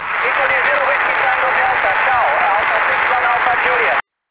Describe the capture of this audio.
Hear His DSignal in Rome!